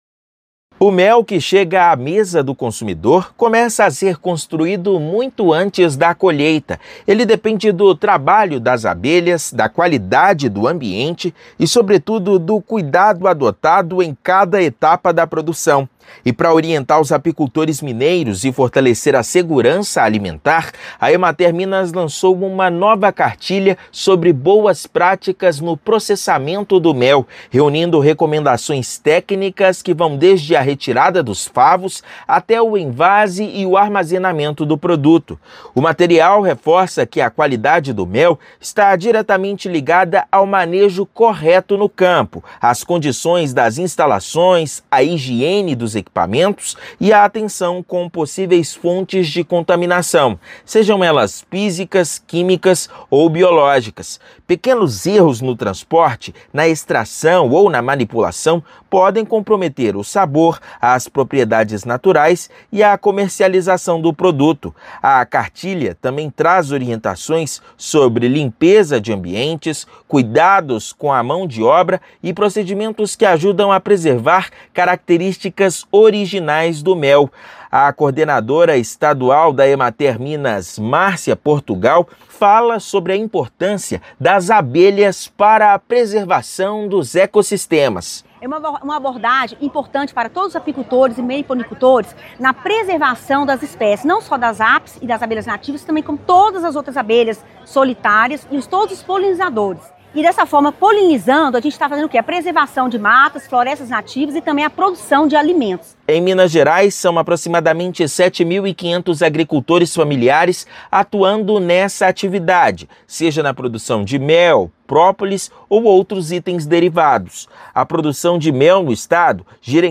Publicação destinada aos apicultores traz dicas sobre qualidade, segurança alimentar e prevenção de contaminações. Ouça matéria de rádio.